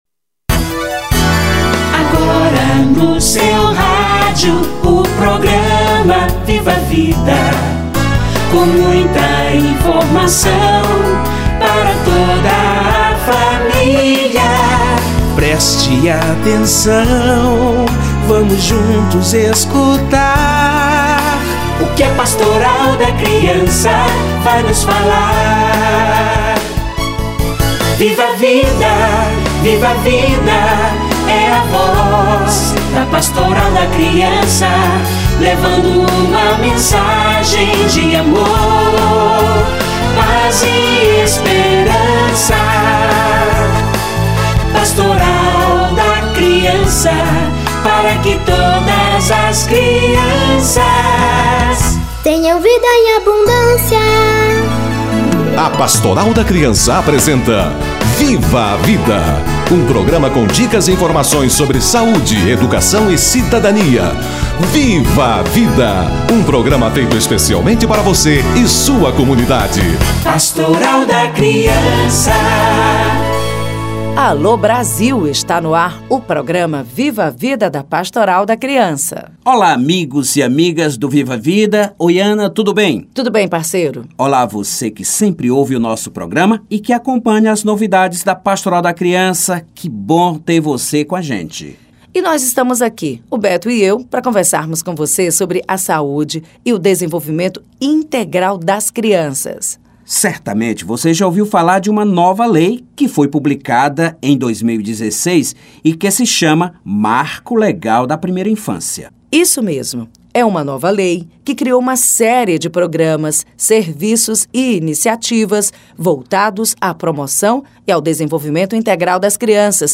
Marco Legal da Primeira Infância - Entrevista